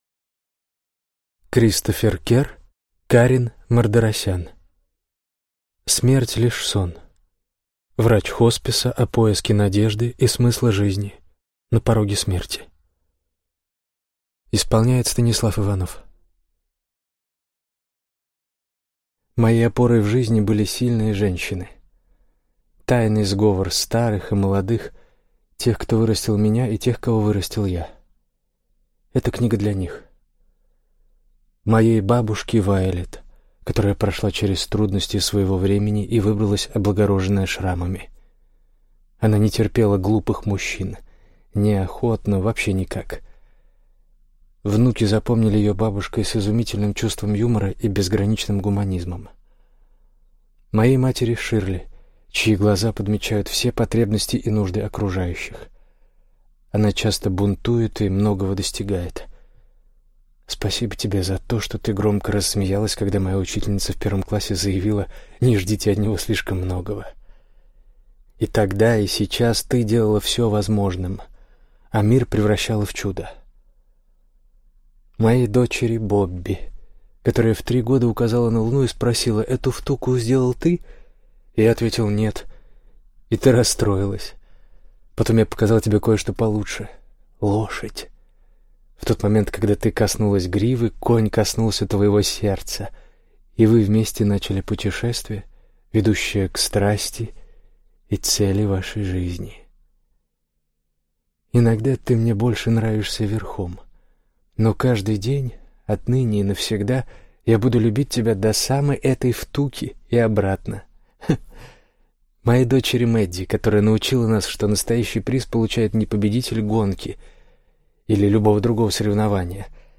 Аудиокнига Смерть – лишь сон. Врач хосписа о поиске надежды и смысла жизни на пороге смерти | Библиотека аудиокниг